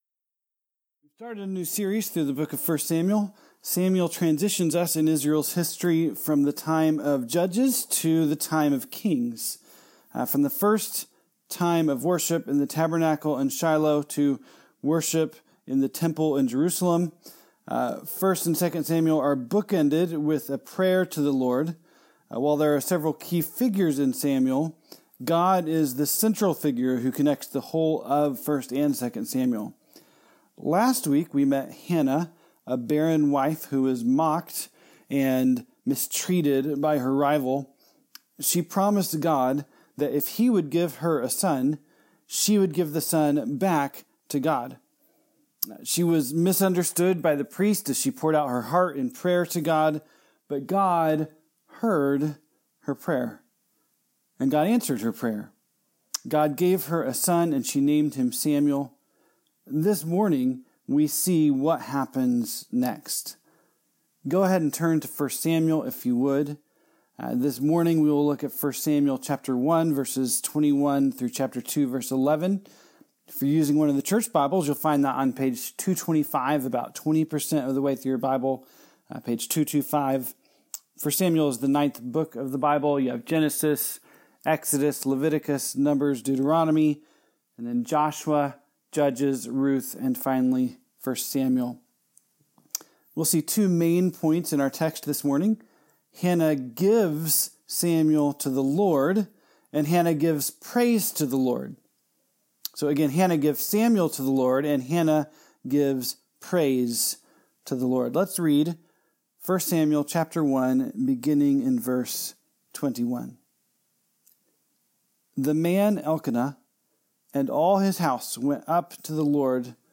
2020 ( Sunday AM ) Bible Text